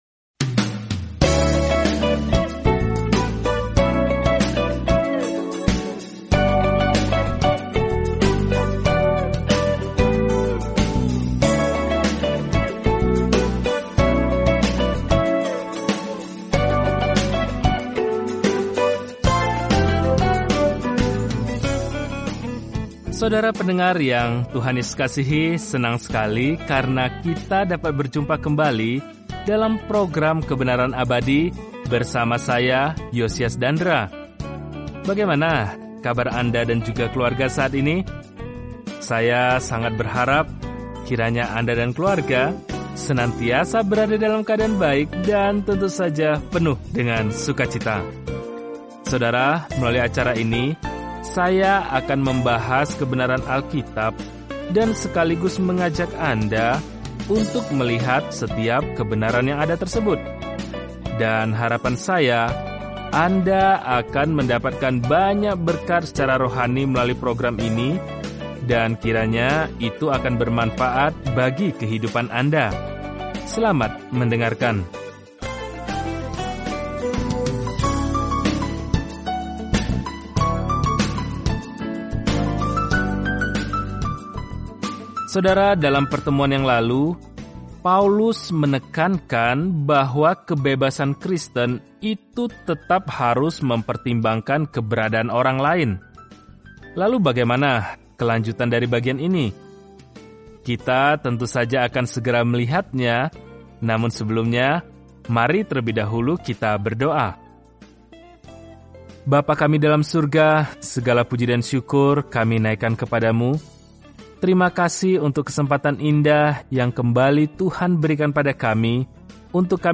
Apakah topik tersebut dibahas dalam surat pertama kepada jemaat di Korintus, memberikan perhatian praktis dan koreksi terhadap permasalahan yang dihadapi kaum muda Kristen. Telusuri 1 Korintus setiap hari sambil mendengarkan pelajaran audio dan membaca ayat-ayat tertentu dari firman Tuhan.